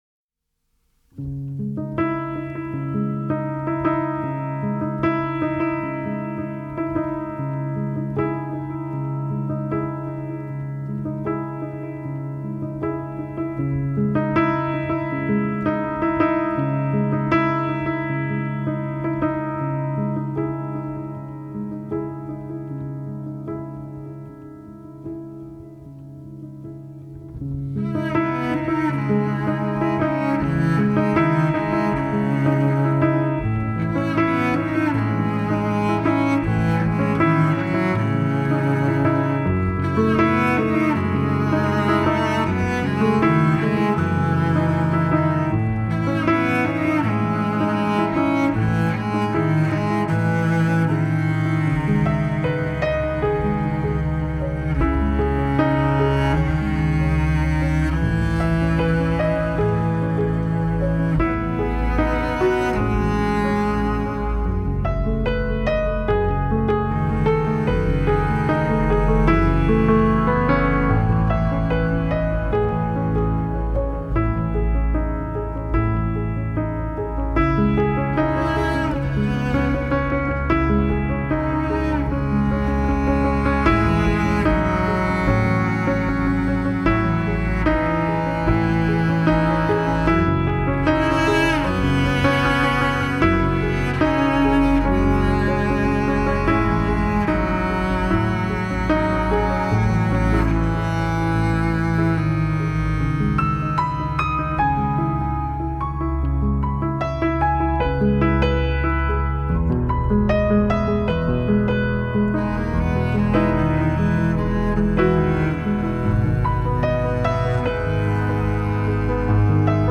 спокойная-музыка-3.mp3
Жанр: Classical